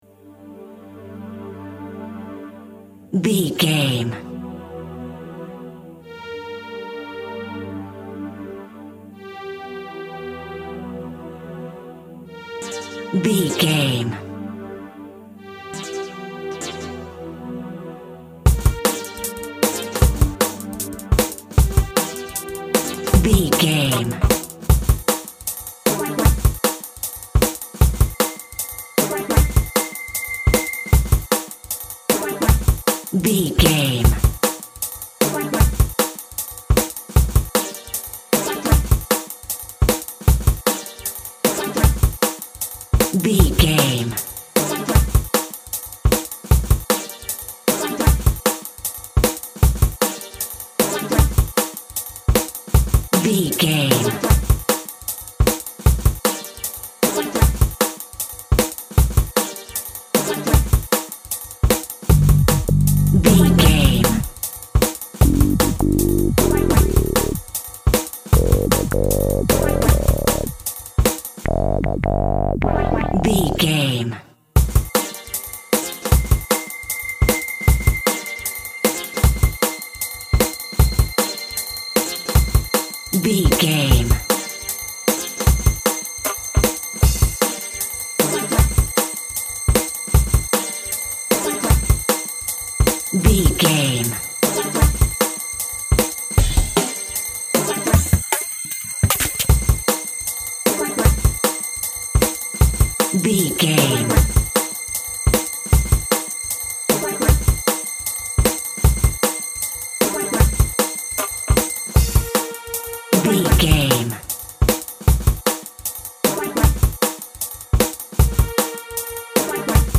Drum & Bass in Space.
Atonal
Fast
futuristic
hypnotic
industrial
dreamy
frantic
powerful
drums
synthesiser
chill out
nu jazz
synth lead
synth bass